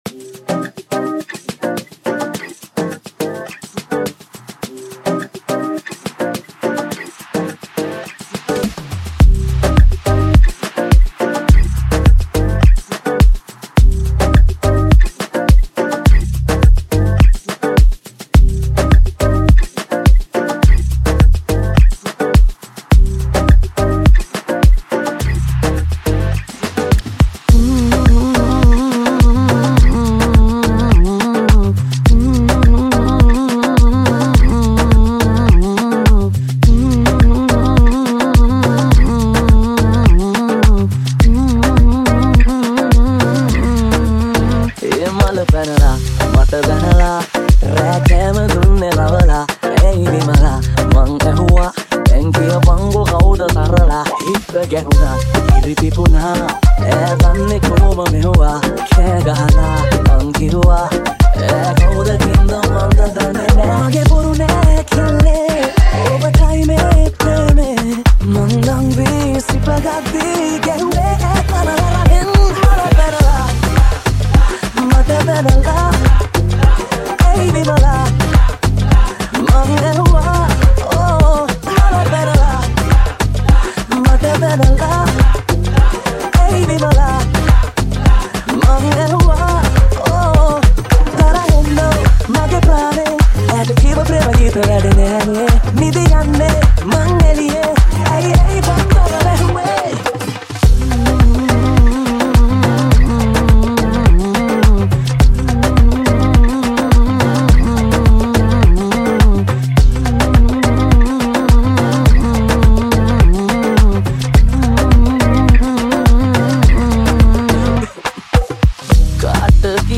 Afro-Panjab Remix